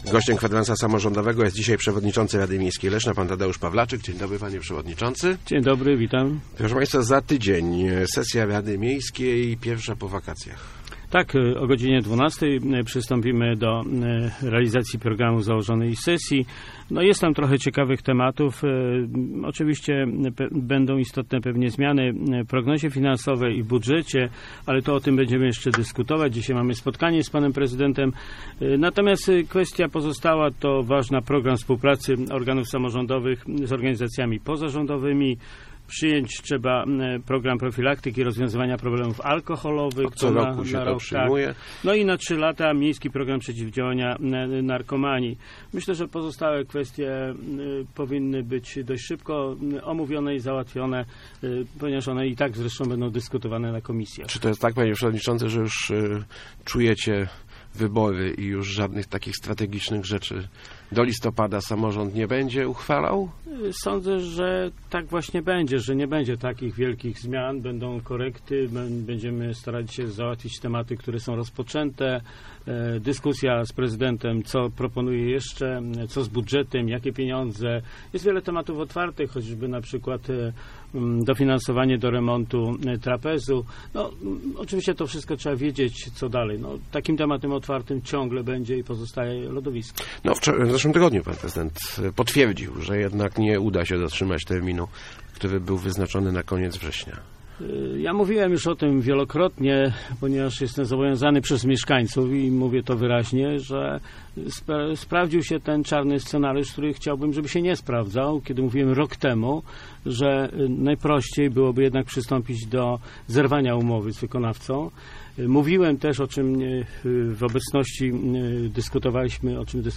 Gościem Kwadransa był Tadeusz Pawlaczyk, przewodniczący RML